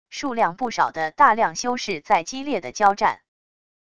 数量不少的大量修士在激烈的交战wav音频